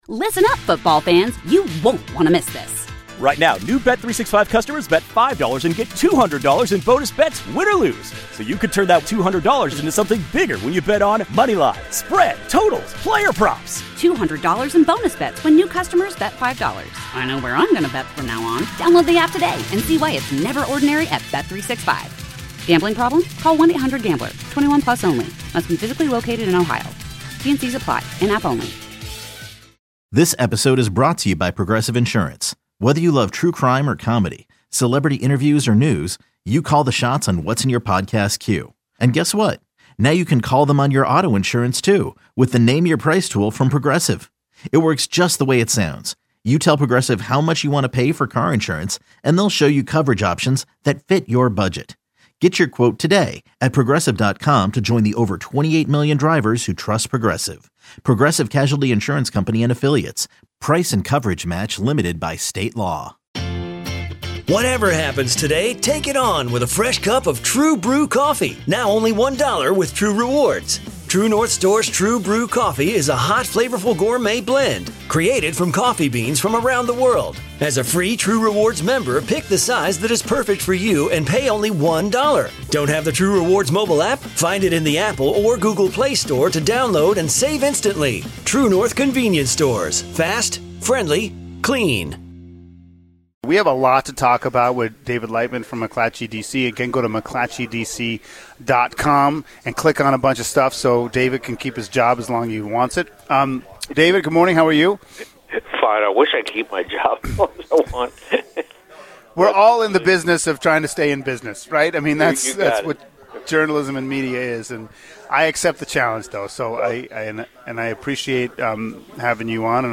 News, weather, traffic and sports plus, interviews with the people making the news each day.